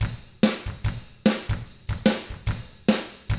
146ROCK T1-L.wav